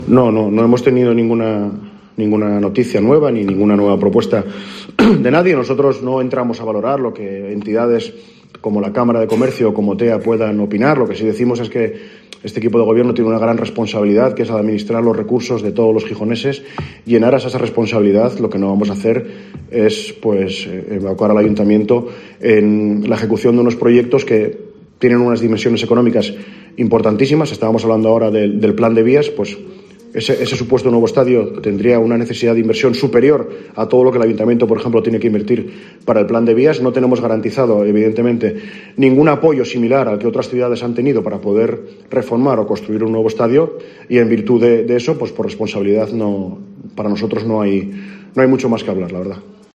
ESCUCHA AQUÍ LAS DECLARACIONES DE JESÚS MARTÍNEZ SALVADOR